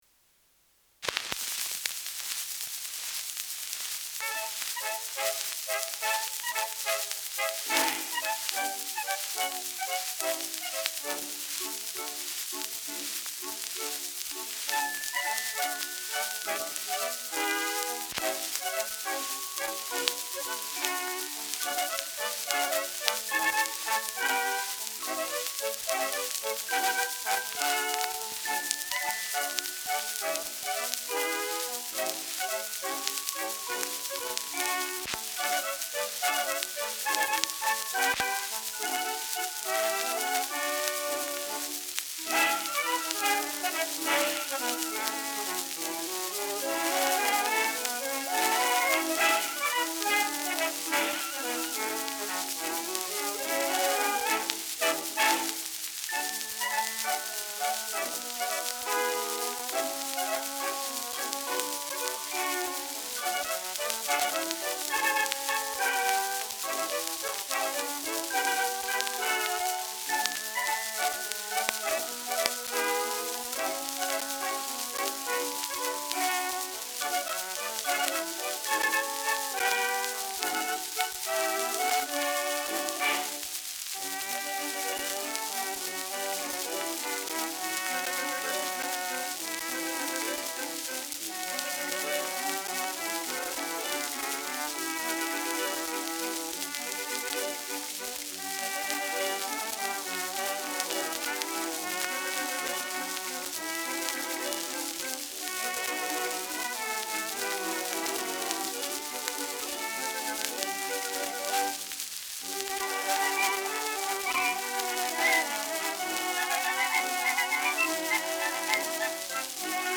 Schellackplatte
[unbekanntes Ensemble] (Interpretation)